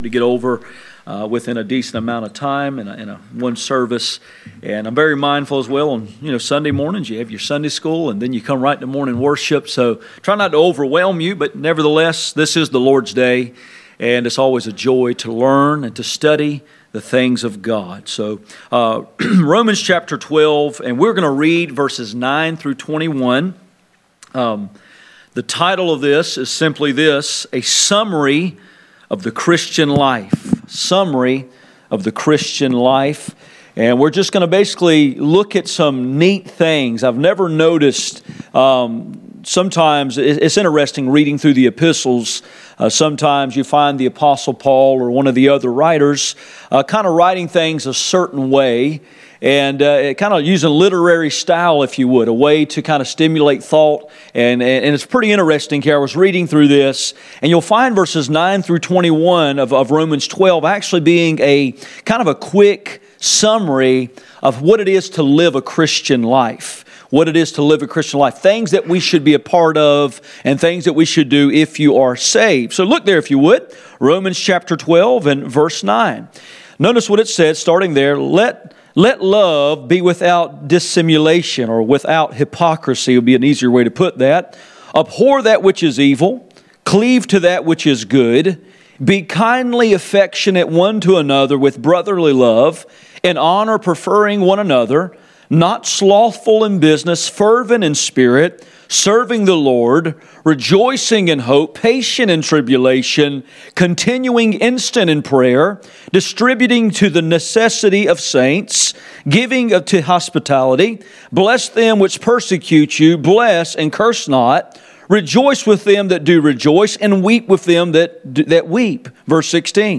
None Passage: Romans 12:9-21 Service Type: Sunday Morning %todo_render% « Its time to go back A summary of the Christian Life